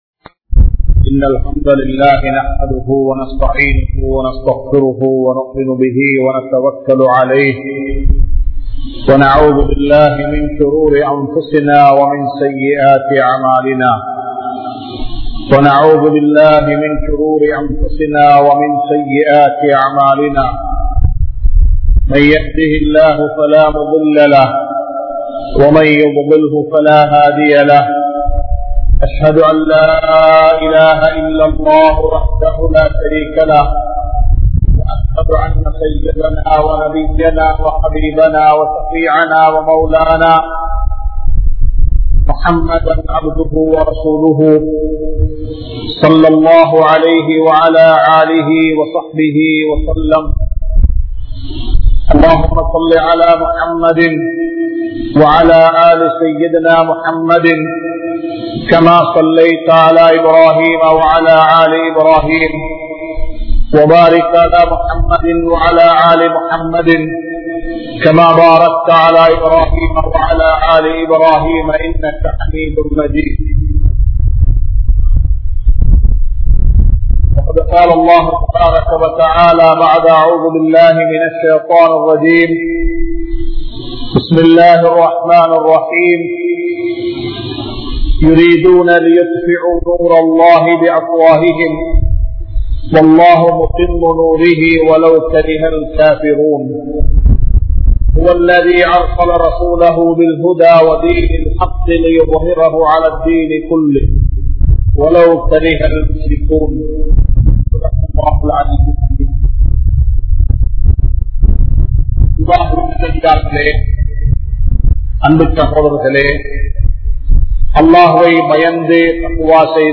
Thaqwa`vai Uruvaakkum Arivu (தக்வாவை உருவாக்கும் அறிவு) | Audio Bayans | All Ceylon Muslim Youth Community | Addalaichenai
Muhideen (Markaz) Jumua Masjith